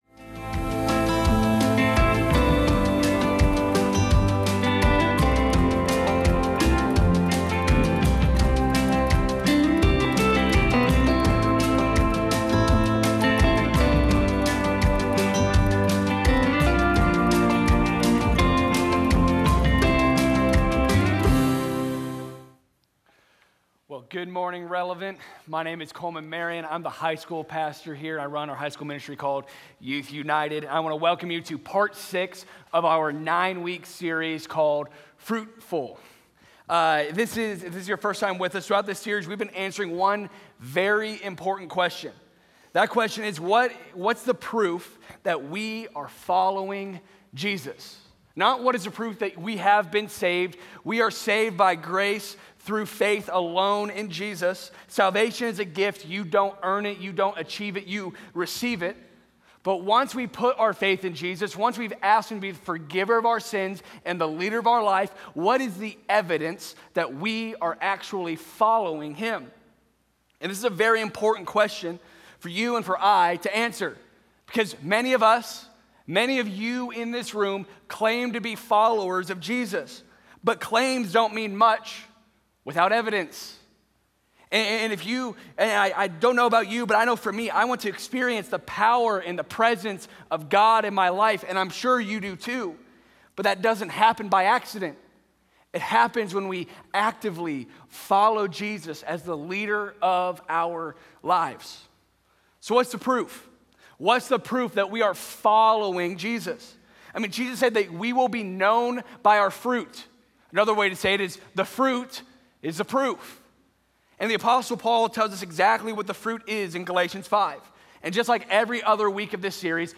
Sunday Sermons FruitFULL, Week 6: "Goodness" Mar 01 2026 | 00:34:07 1x 00:00 / 00:34:07 Subscribe Share Apple Podcasts Spotify Overcast RSS Feed Share Link Embed